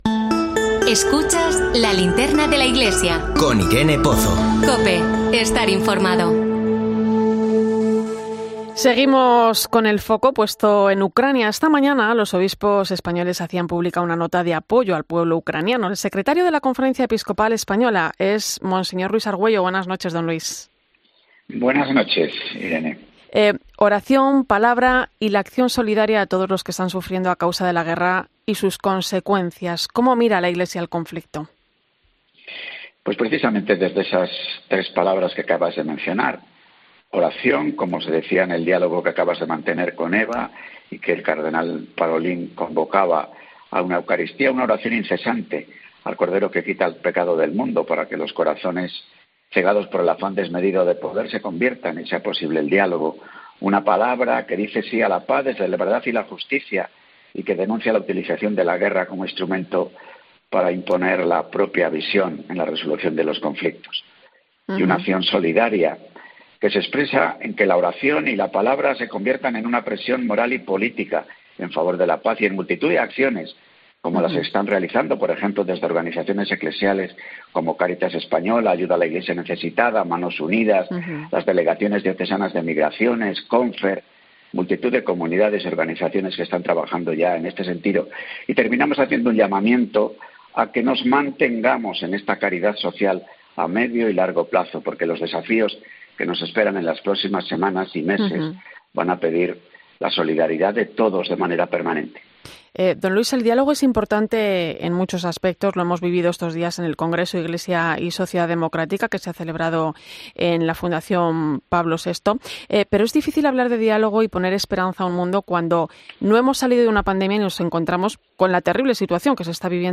Es uno de los asuntos que ha abordado el secretario general de la Conferencia Episcopal Española, monseñor Luis Argüello, en una entrevista en 'La Linterna de la Iglesia' en COPE.